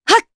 Hilda-Vox_Attack1_jp.wav